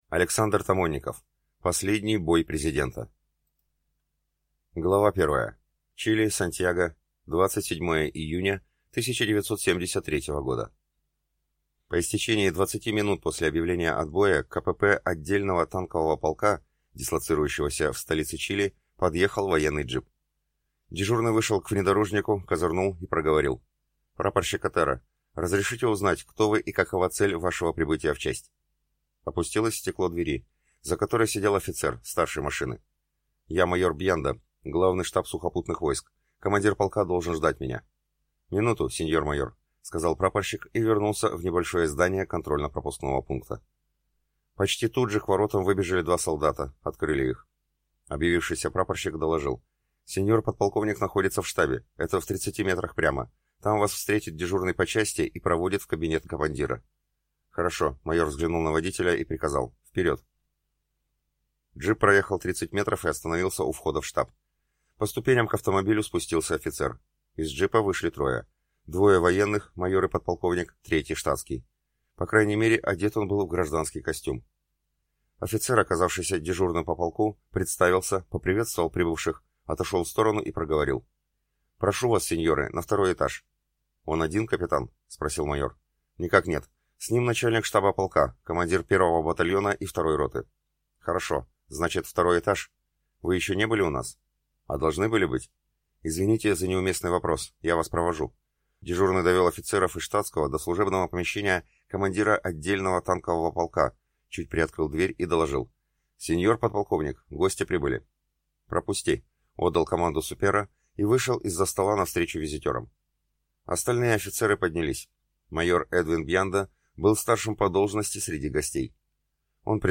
Аудиокнига Последний бой президента | Библиотека аудиокниг
Прослушать и бесплатно скачать фрагмент аудиокниги